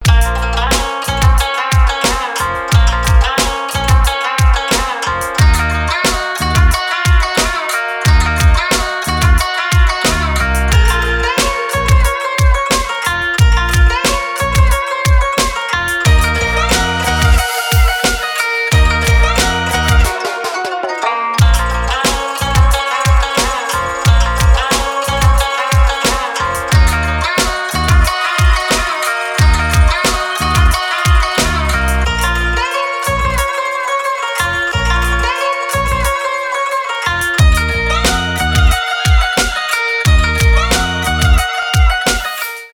хип-хоп , без слов , рэп